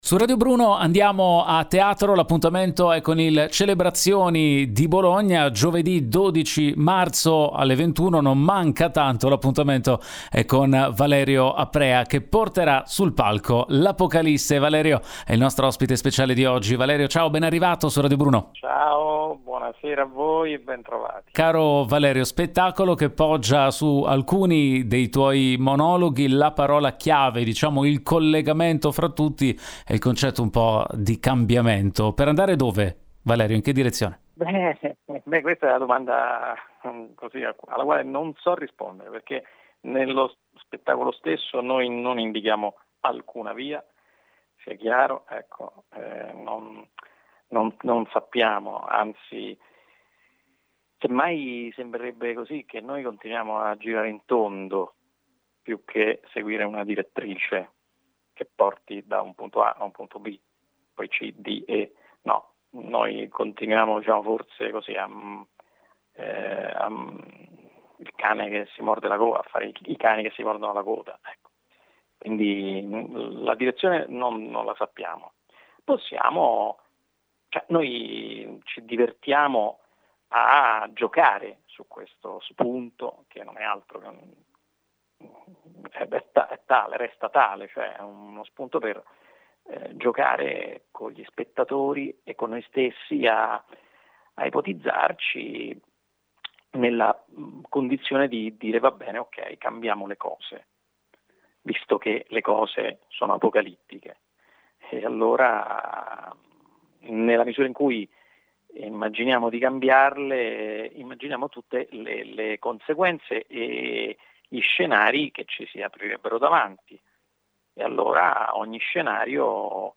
Home Magazine Interviste Valerio Aprea presenta “Lapocalisse”